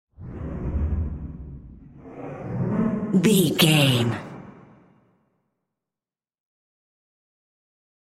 In-crescendo
Thriller
Aeolian/Minor
scary
ominous
dark
eerie
strings
synth
pads